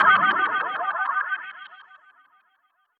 Transition FX-2.wav